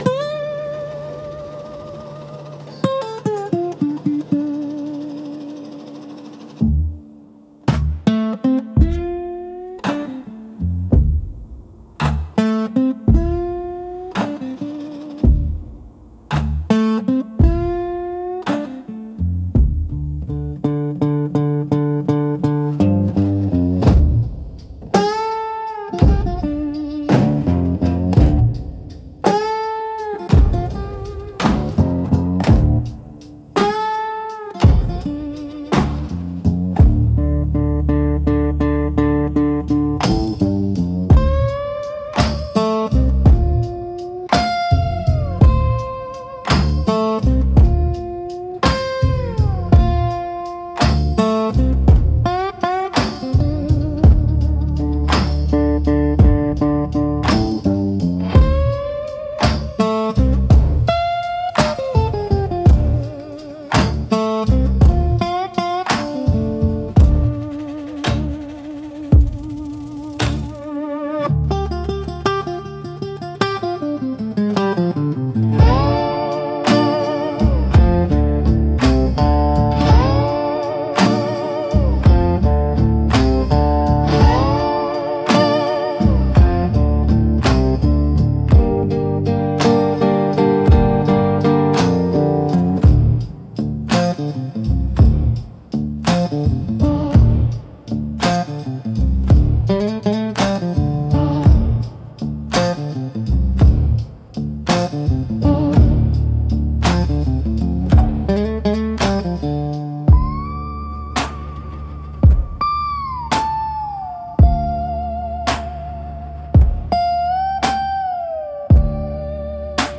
Instrumental - Broken Promises. 2.25